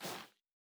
Bare Step Snow Medium D.wav